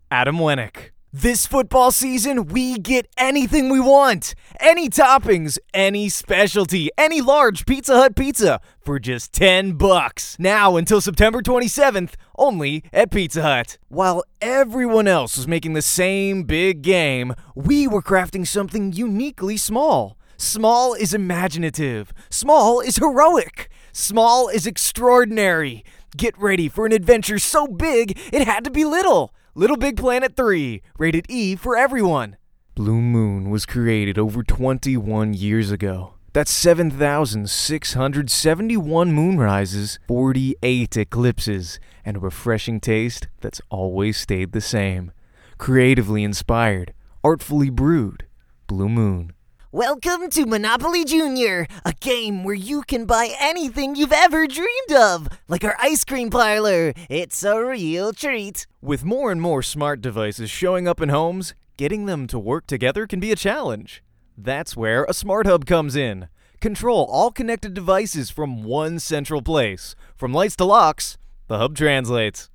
Male
If you need an honest, genuine, relatable, young adult, I'm the man for the job!
Studio Quality Sample
Recordings From My Studio
Words that describe my voice are Genuine, John Krasinski Type, Friendly.
0309Dry_Commercial_Demo.mp3